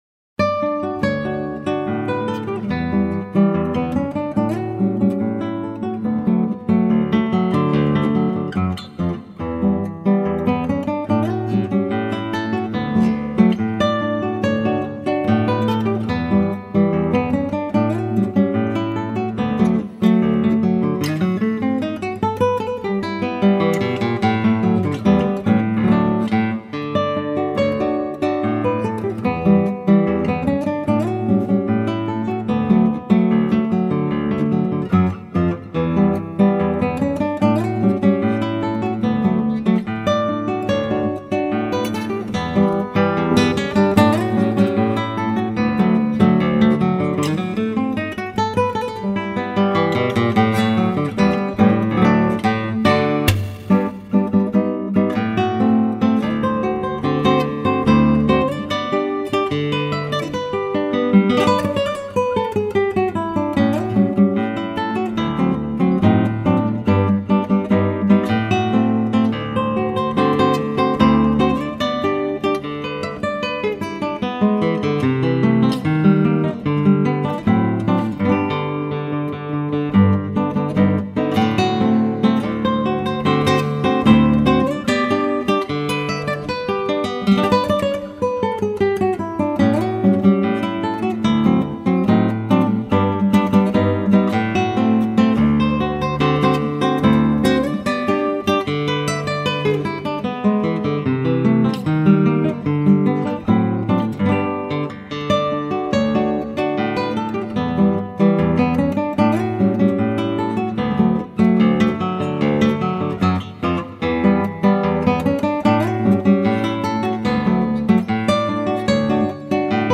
02:18:00   Instrumental